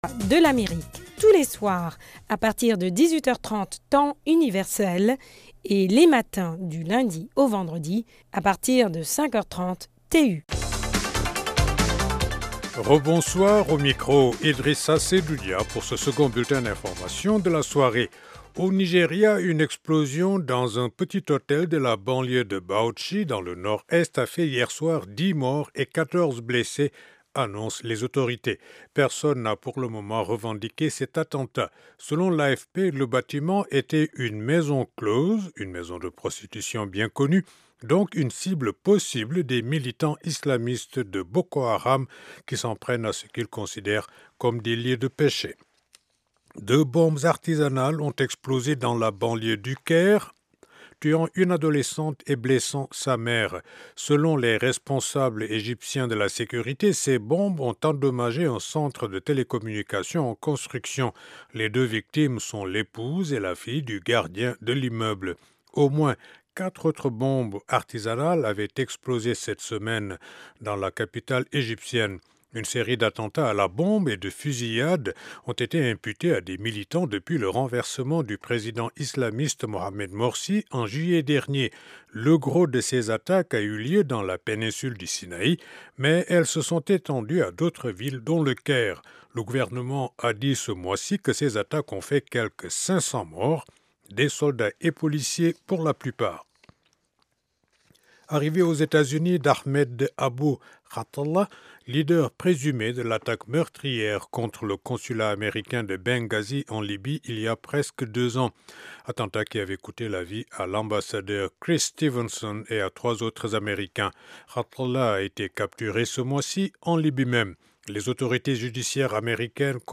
Dans l'édition du 28 juin on parle aussi de la scolarisation des enfants réfugiés dans l'est de la RDC.(5mn d'actualité du jour en début d'émission)